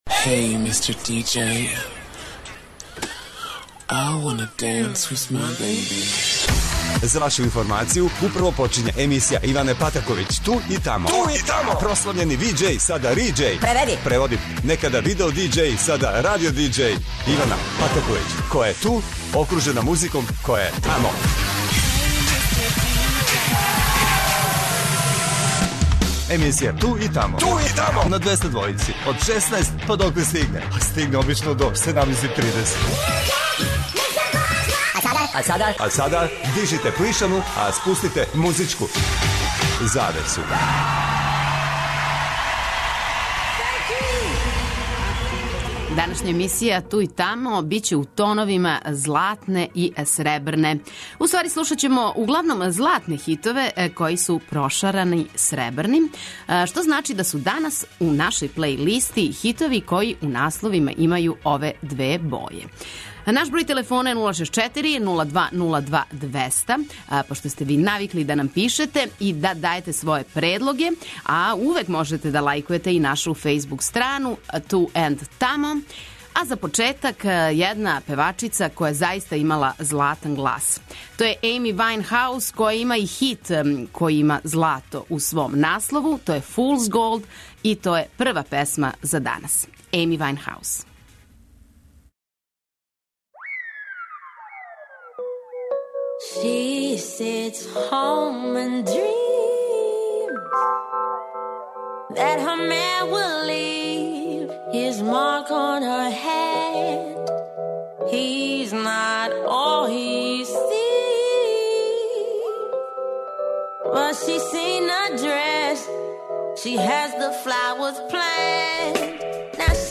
За слушаоце Двестадвојке спремна је плејлиста са домаћим и страним песмама које у насловима имају баш ове две распеване боје.